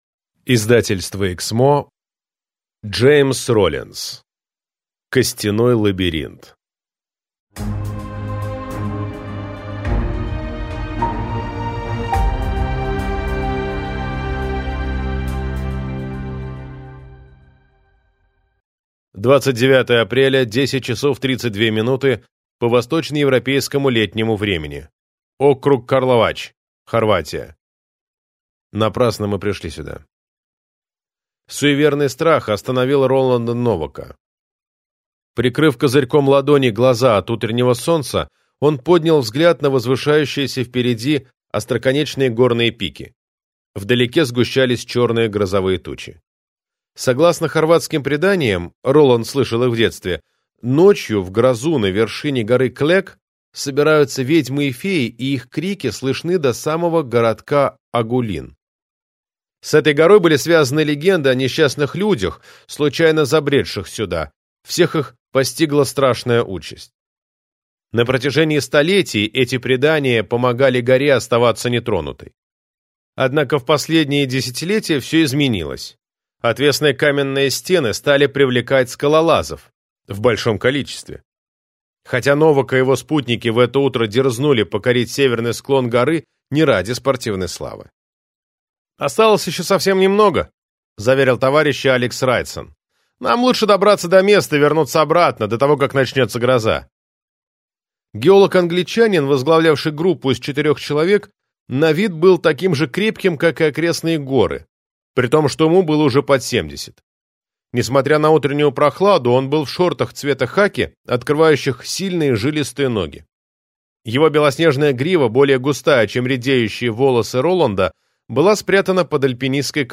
Аудиокнига Костяной лабиринт - купить, скачать и слушать онлайн | КнигоПоиск